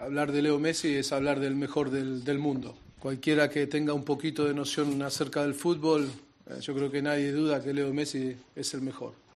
El entrenador del Tottenham habló de su compatriota Leo Messi en la previa del encuentro ante el Barcelona.